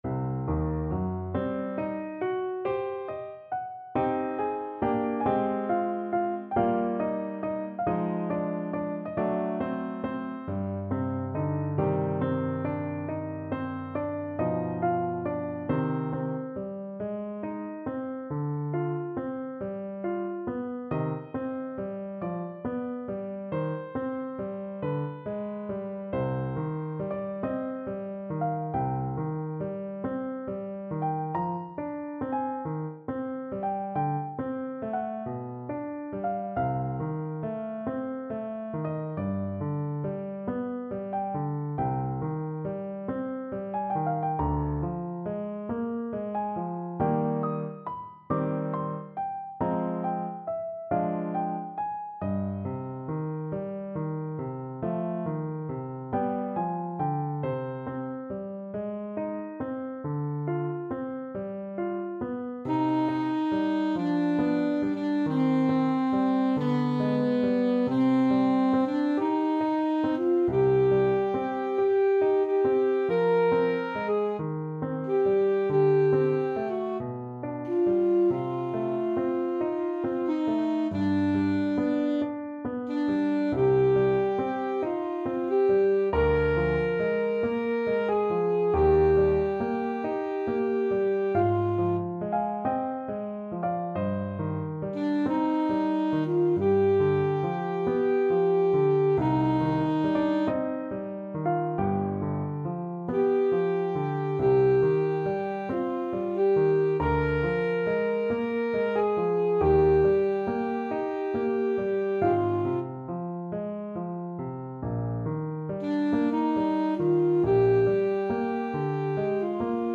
Alto Saxophone
Slow =c.46
C minor (Sounding Pitch) A minor (Alto Saxophone in Eb) (View more C minor Music for Saxophone )
4/4 (View more 4/4 Music)
B4-F6
Classical (View more Classical Saxophone Music)